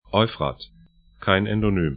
Euphrat 'ɔyfrat Nahr al Furāt 'naxər al fu'ra:t ar Fluss / stream 31°00'N, 47°25'E